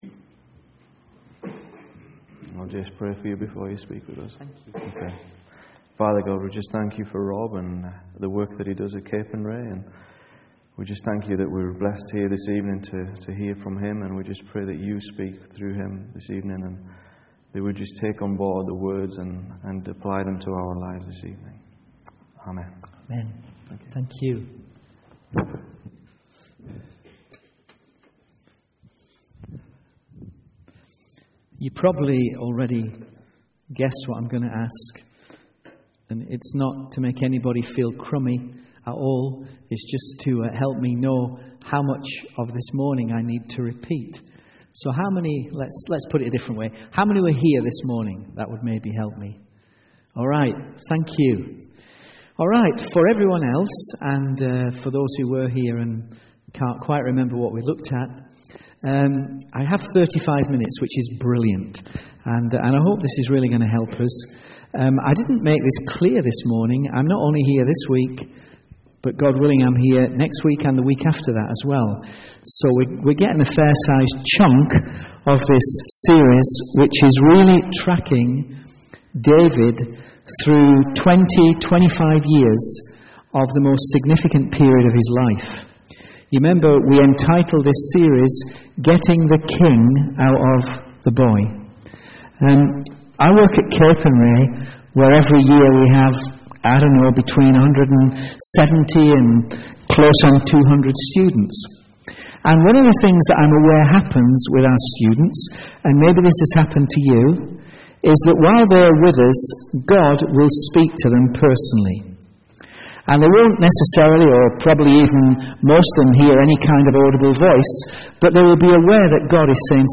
From Series: "Sunday Evening"